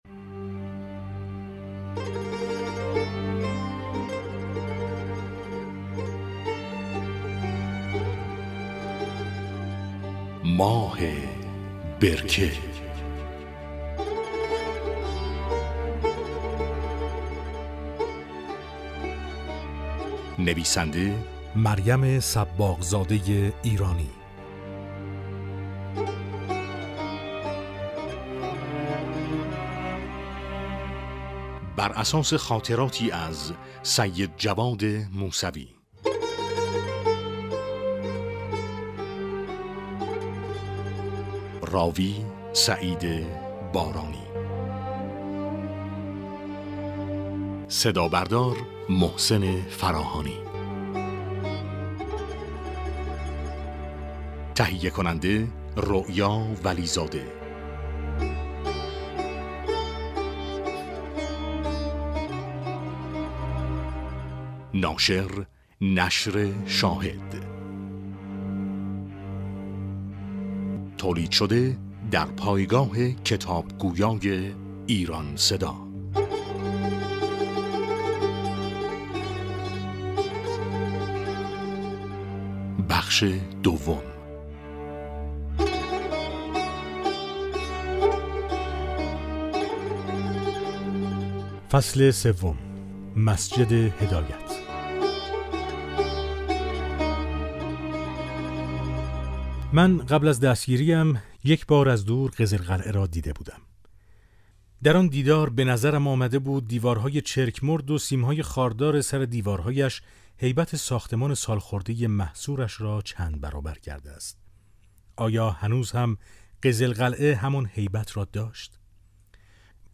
امکان دانلود رایگان کتاب صوتی «ماه برکه» فراهم شد
نوید شاهد - امکان دانلود رایگان کتاب صوتی «ماه برکه» که از آثار «نشر شاهد» است، در پایگاه خبری نوید شاهد فراهم شد.